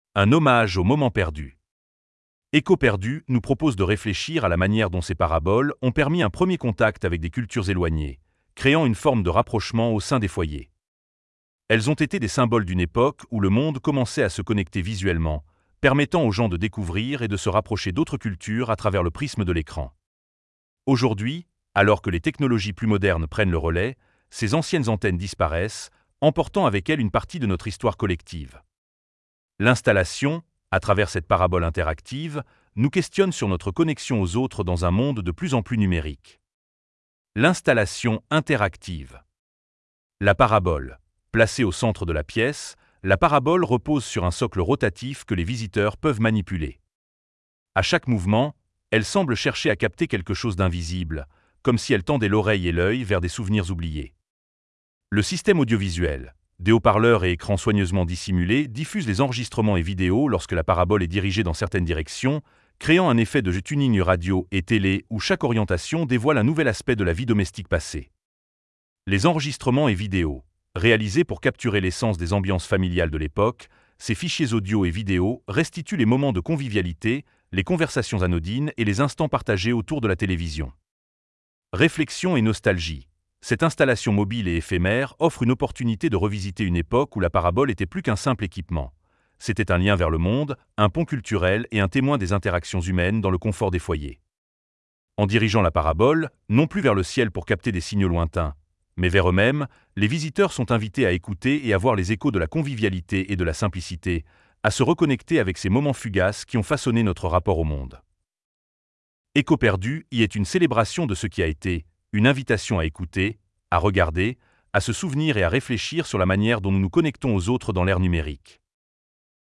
• Daily Conversations: The murmurs of a family gathered around the television for dinner, laughter and comments on the shows watched together.
• Videos of TV Shows: The distant echoes of presenters’ voices, dialogues from series or sound effects from shows, now enriched with images of these moments.
• Ambient Sounds and Images of Homes: The clinking of utensils, the crackling of a radio playing in the background, discussions overlaying the sounds and images of the television.